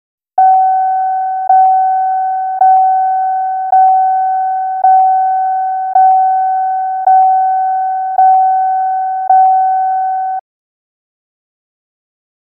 Door / Chime | Sneak On The Lot
Car Door Open Chime; Electronic Warning Chimes. Close Perspective.